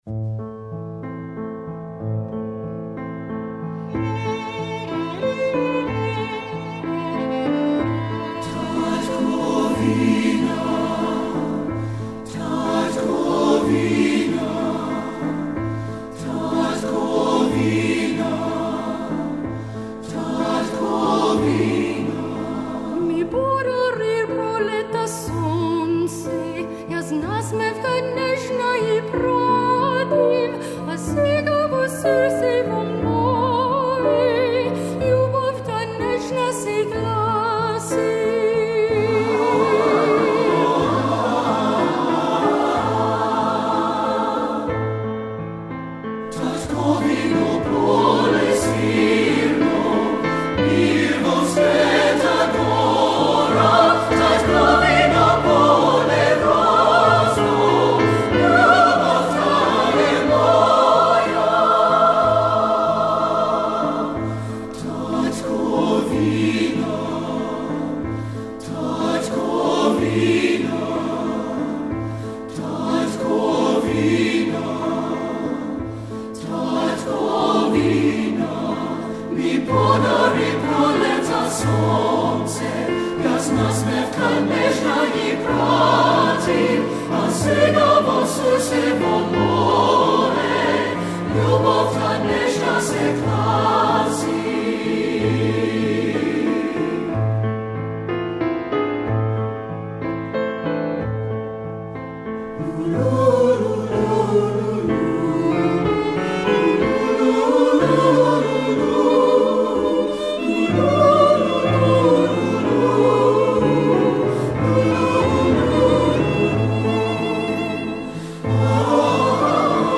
Composer: Macedonian Folk Song
Voicing: SATB